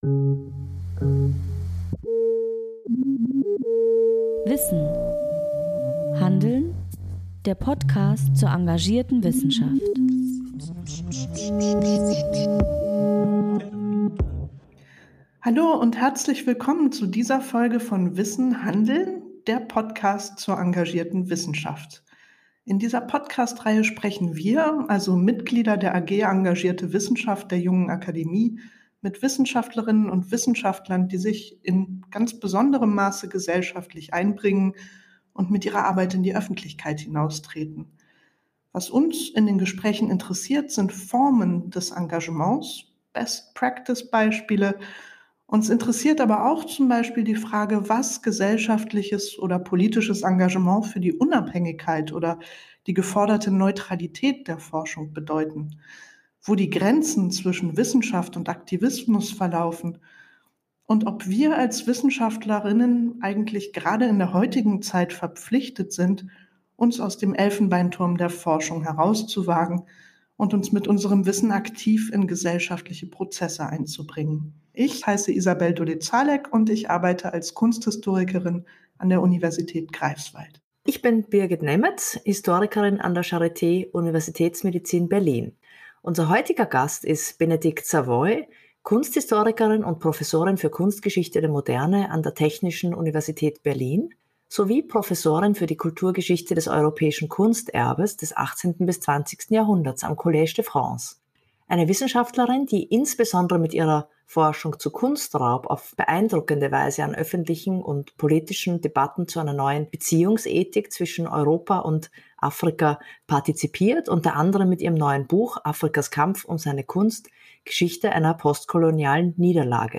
Im Gespräch teilt sie Erfahrungen und Einsichten, die sie in Bezug auf ihr gesellschaftliches Engagement gesammelt hat.